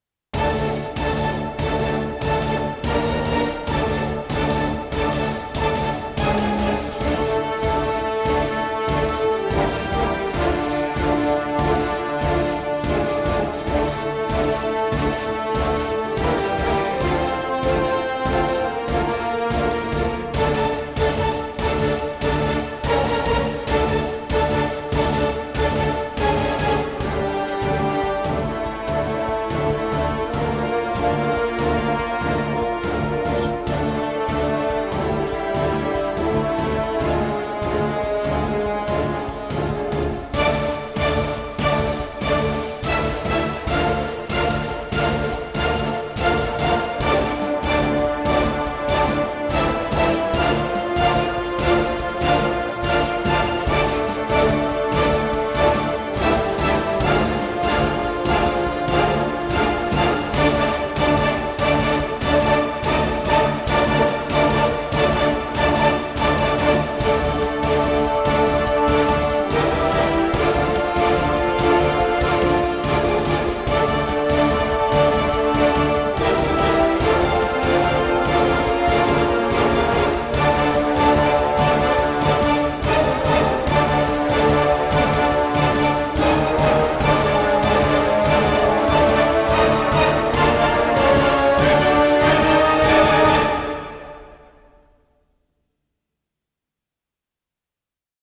Epic orchestral track for boss battle and cinematic.